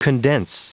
Prononciation du mot condense en anglais (fichier audio)